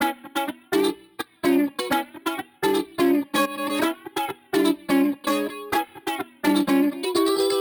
Reiko_E_126_dry.wav